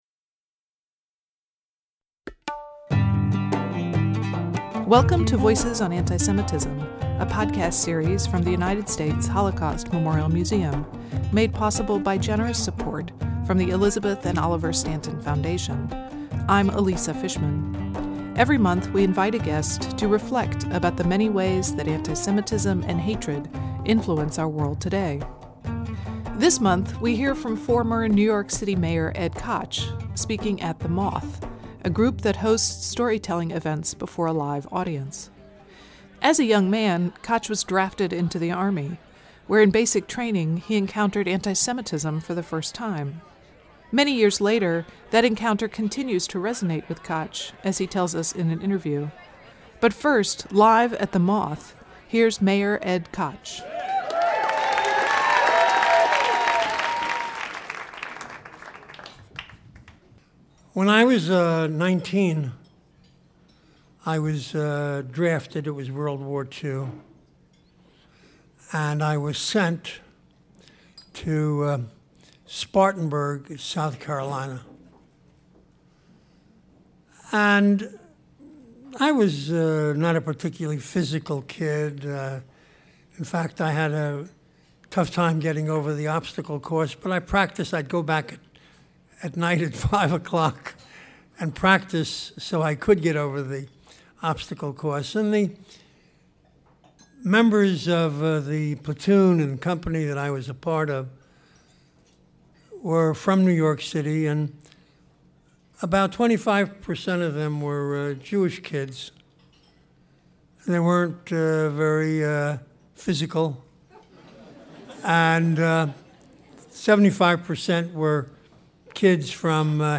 This month, we hear from former New York City Mayor Ed Koch, speaking at The Moth (external link) , a group that hosts storytelling events before a live audience.